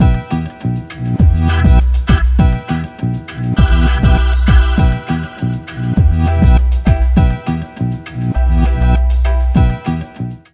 RAGGALOOP2-L.wav